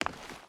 Footsteps / Stone
Stone Run 4.wav